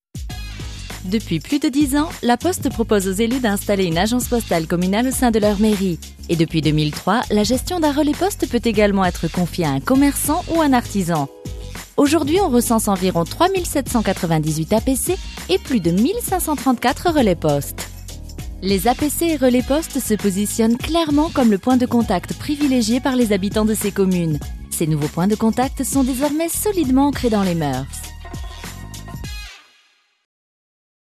Découvrez une voix féminine aux timbres multiples : douce et élégante pour les documentaires et narrations, pleine d’énergie et de sourire pour les utilisations commerciales, libre et fantaisiste pour créer les personnages de cartoon dont vous rêvez !
Sprechprobe: Sonstiges (Muttersprache):
I have a versatile voice, happy, full of energy and fun for commercials, soft and elegant for corporate and documentaries, and I love creating imaginary characters.